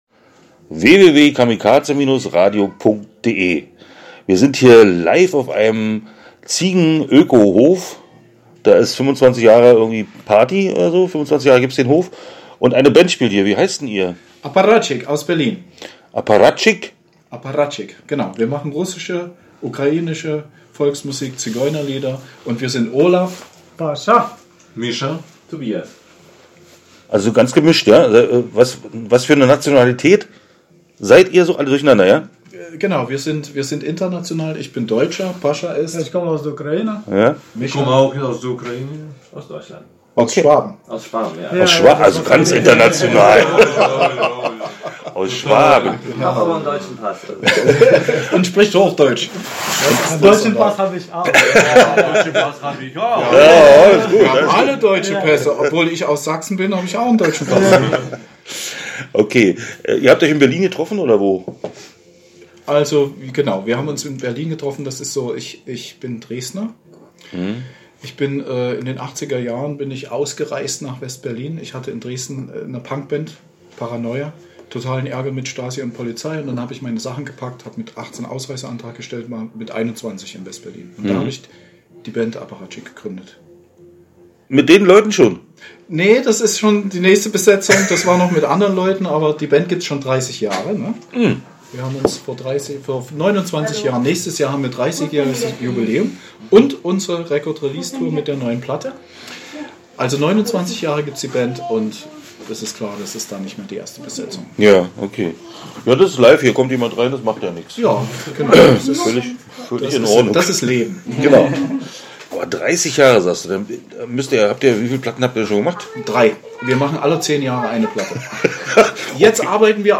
Interview Apparatschik (15:53)